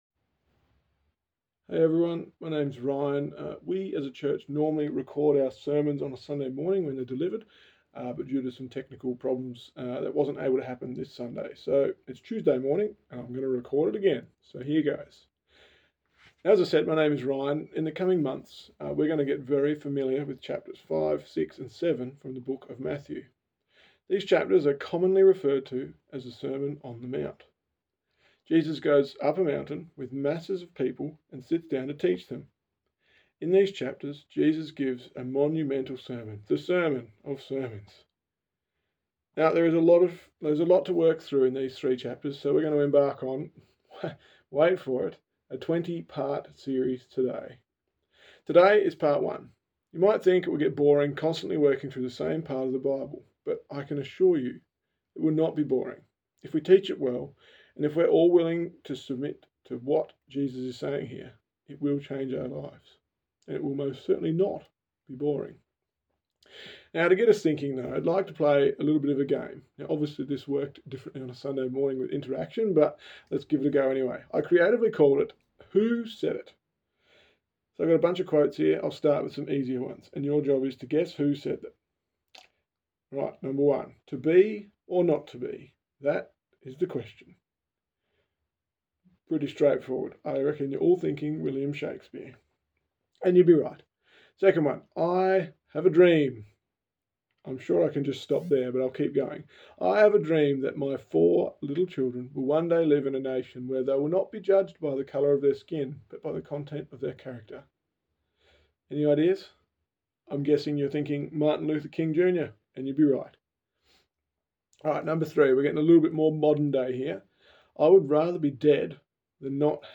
Intro to the sermon on the mount.mp3